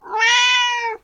gato3b
cat3b.mp3